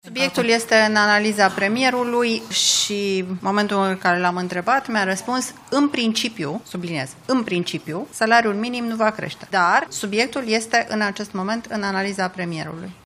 „În principiu” nu crește salariul minim brut de la 1 ianuarie, este reacția Guvernului, după ce liderii PSD au spus că va fi majorat, eventual chiar și până la 4.800 de lei brut, față de 4.050 lei, cât este în prezent. Întrebată de jurnaliști la Palatul Victoria